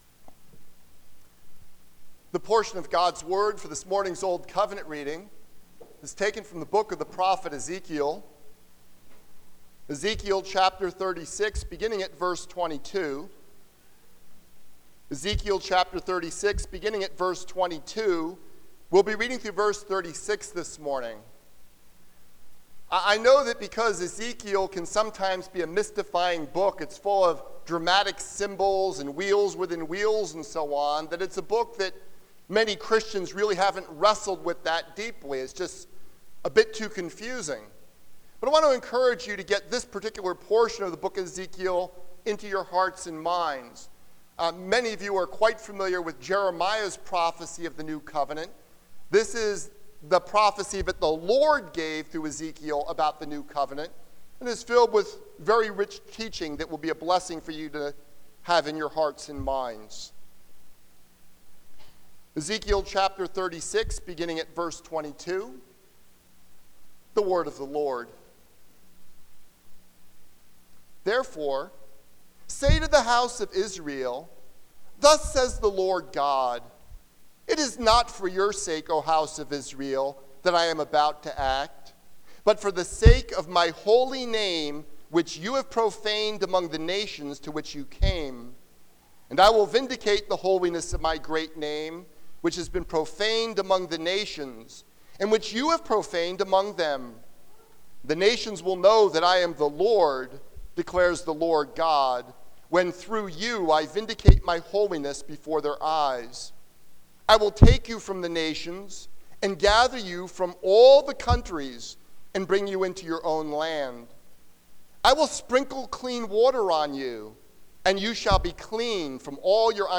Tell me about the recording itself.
Passage: John 13:1-17 Service Type: Sunday Morning